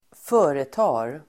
Uttal: [²f'ö:reta:r]